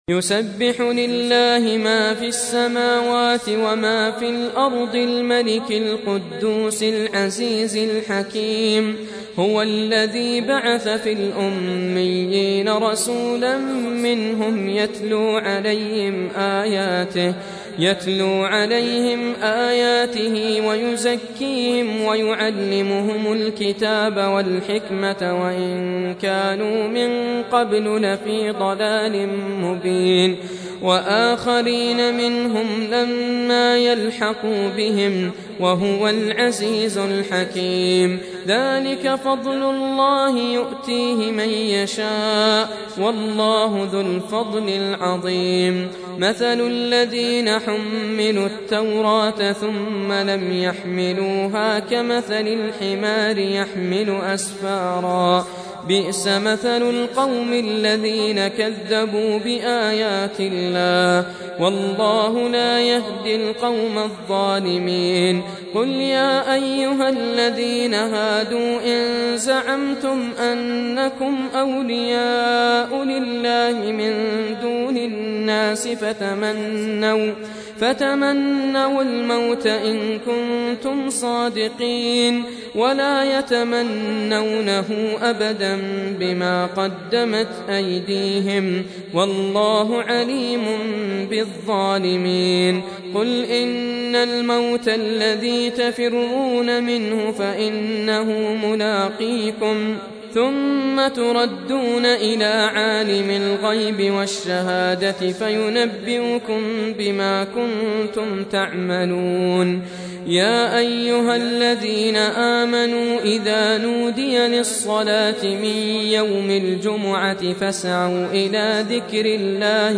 62. Surah Al-Jumu'ah سورة الجمعة Audio Quran Tarteel Recitation
Surah Repeating تكرار السورة Download Surah حمّل السورة Reciting Murattalah Audio for 62.